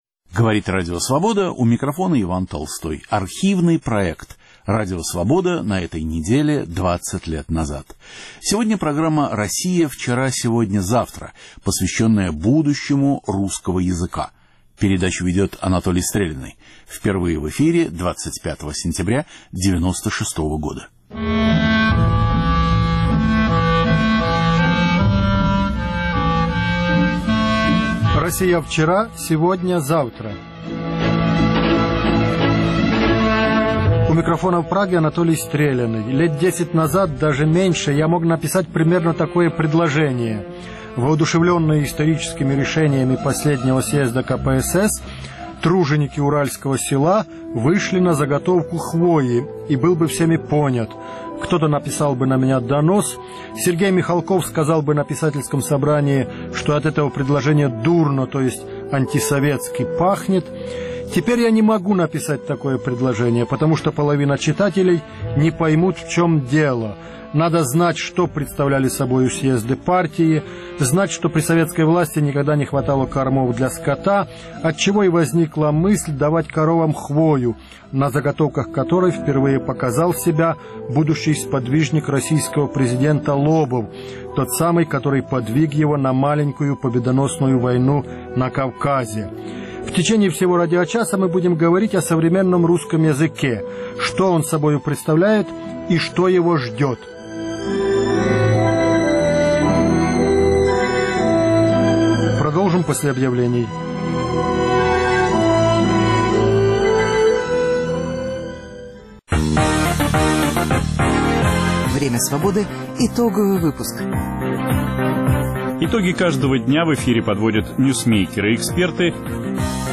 Ведет программу Анатолий Стреляный.